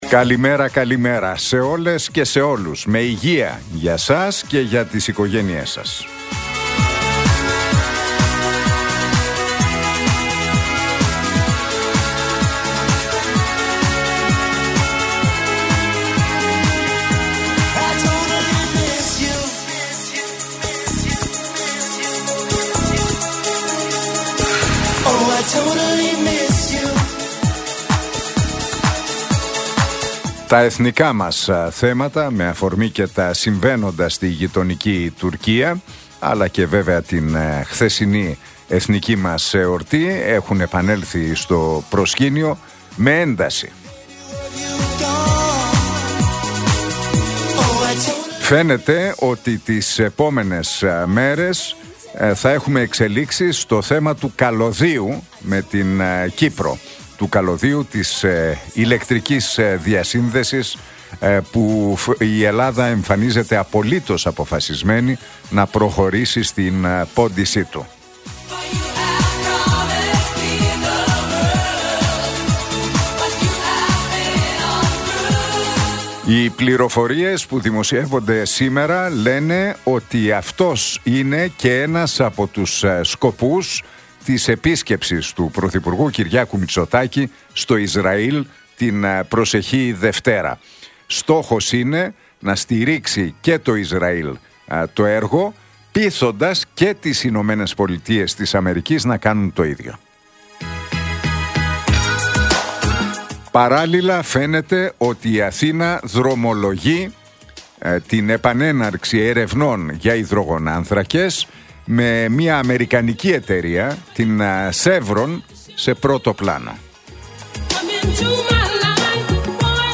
Ακούστε το σχόλιο του Νίκου Χατζηνικολάου στον ραδιοφωνικό σταθμό RealFm 97,8, την Τετάρτη 26 Μαρτίου 2025.